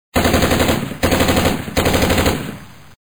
SFX突击枪连续开枪音效下载
SFX音效